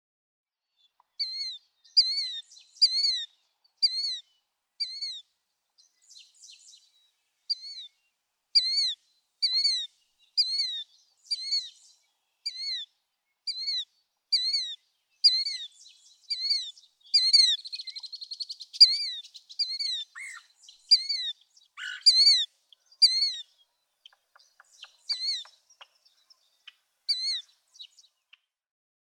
Killdeer
♫34 Kill-deer, Kill-deer, the male in display flight, circling around his territory.
Quivira National Wildlife Refuge, Stafford, Kansas.
034_Killdeer.mp3